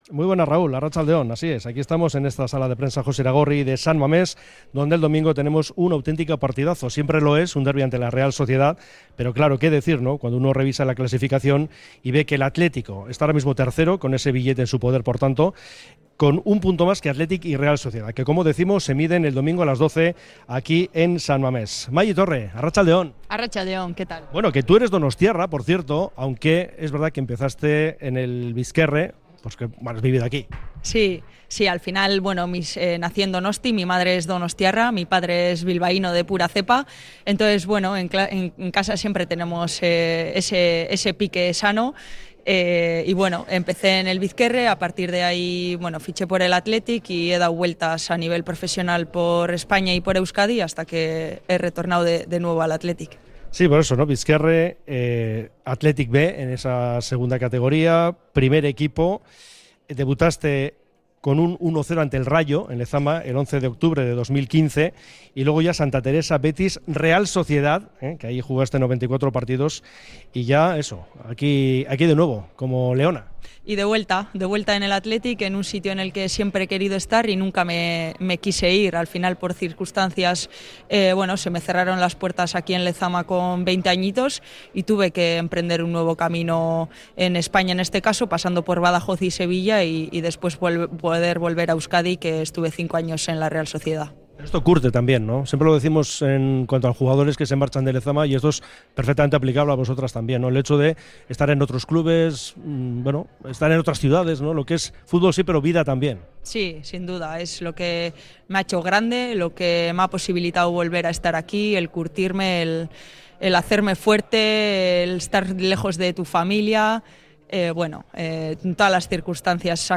Entrevista con la defensa del Athletic antes del derbi de San Mamés